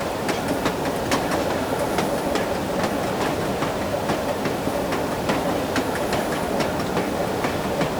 train-wheels-1.ogg